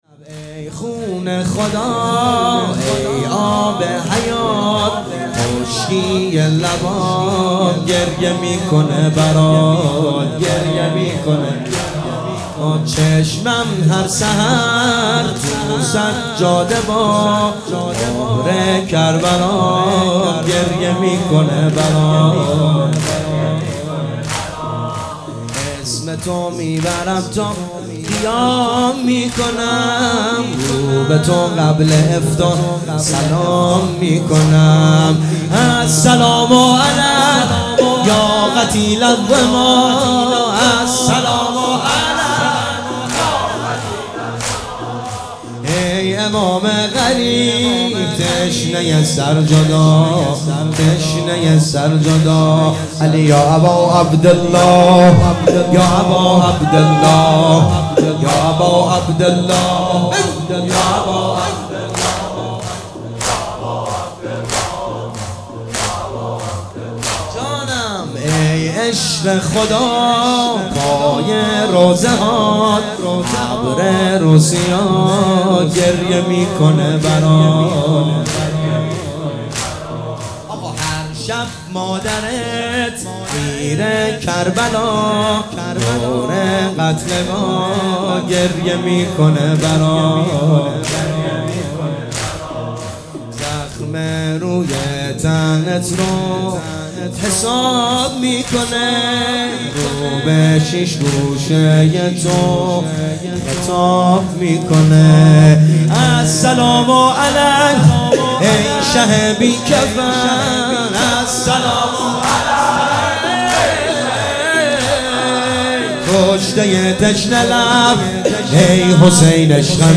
شب نوزدهم رمضان 99 - زمینه - ای خون خدا ای آب حیات